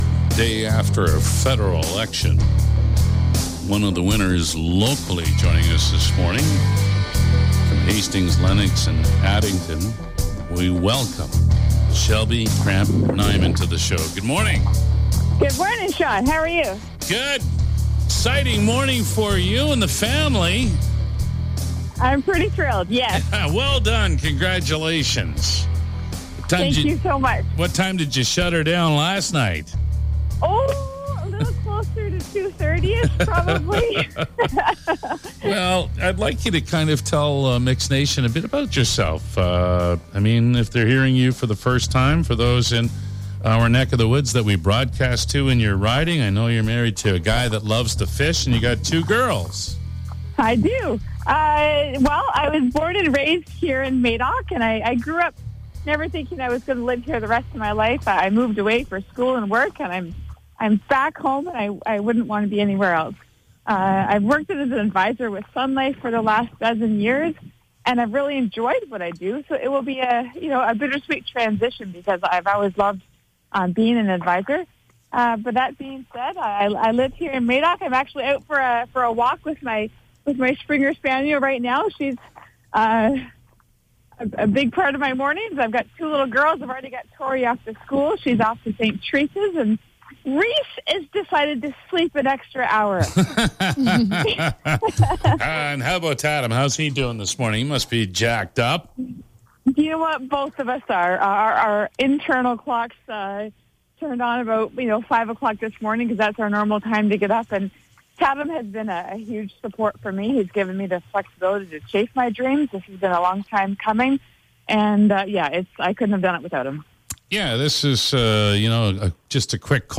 Shelby Kramp-Neuman, New Hastings-Lennox and Addington MP, took the time this morning to speak on The Mix Morning Show!